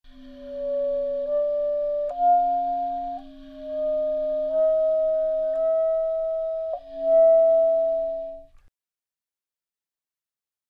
Multiphonic Sequences
A number of multiphonic sequences are quite easy to produce in legato articulation.
•  Moderate tempo; any order (click on music for mp3)